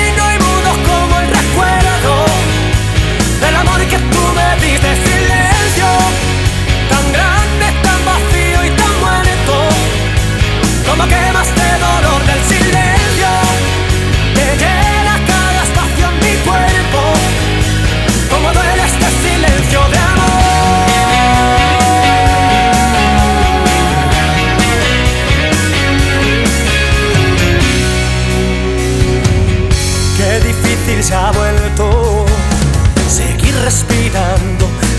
Жанр: Латиноамериканская музыка